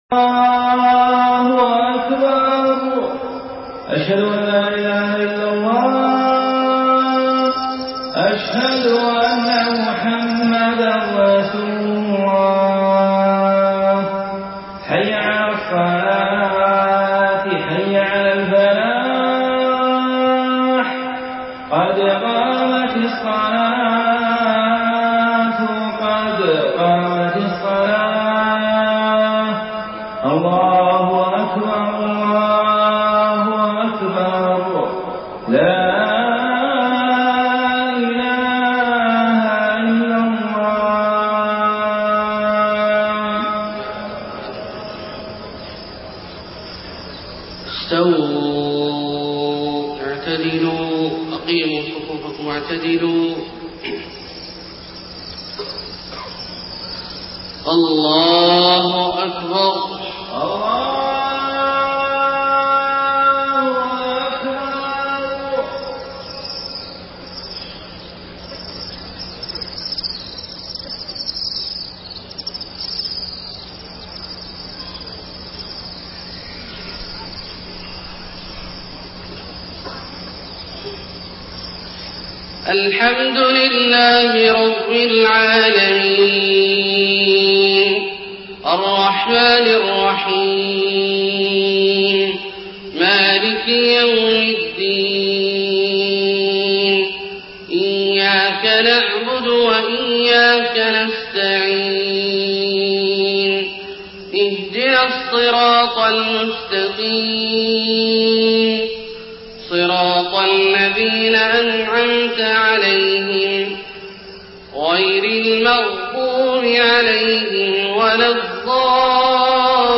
صلاة الفجر 30 صفر 1431هـ خواتيم سورة الحج 63-78 > 1431 🕋 > الفروض - تلاوات الحرمين